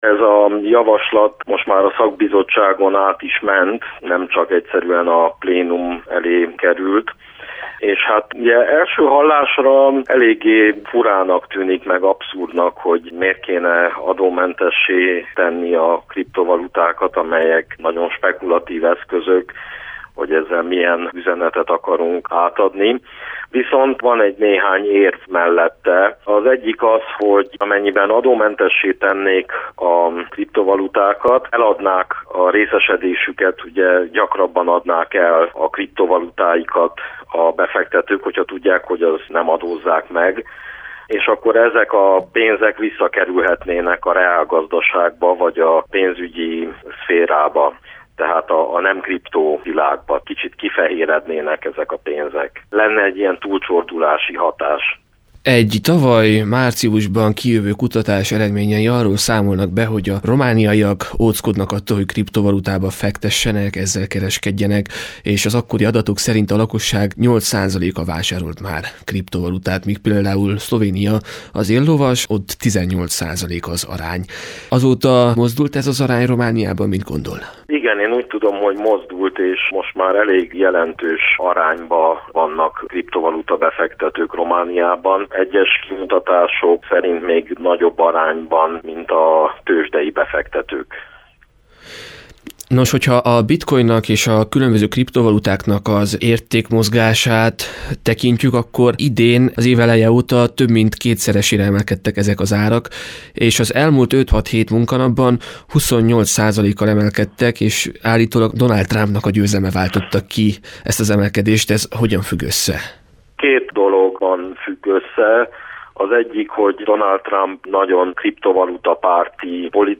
Amerikában ezt ígéri Donald Trump megválasztott elnök, nálunk adómentessé tennék a kriptovaluta alapú befektetéseket. Közgazdászt kérdeztünk.